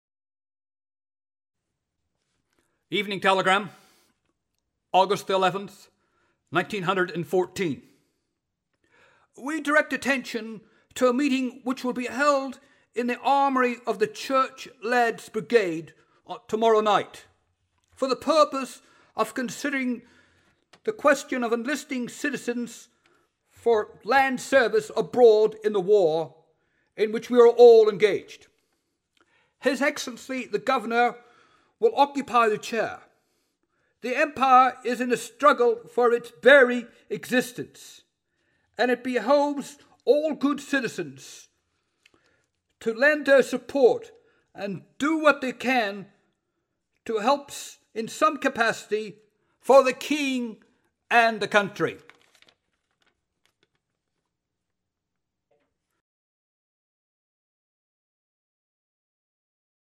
Speech #5: The call to join up for King and Country